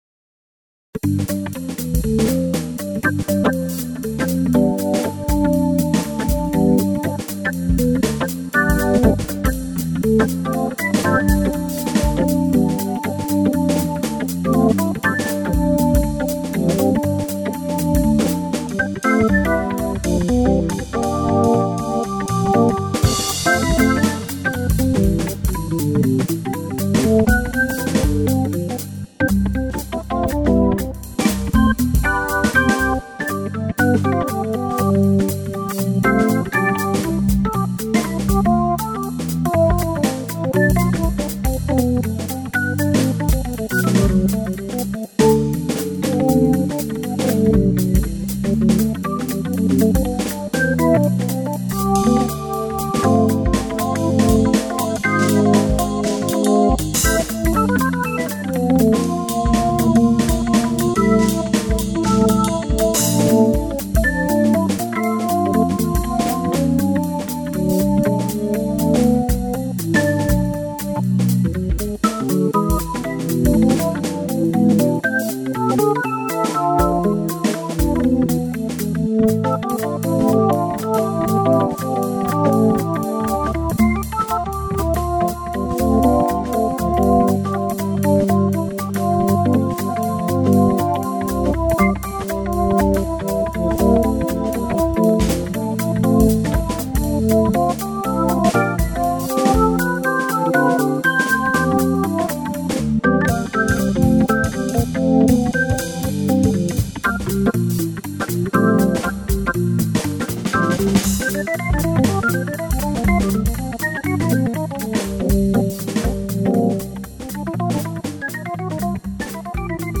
Um mehr Zeit und Raum zur Improvisation zu haben, habe ich erst einmal alles in Halftime gefühlt/gespielt. 2-stimmige Invention Nr.1 --> Anhänge inventio01.mp3 2,6 MB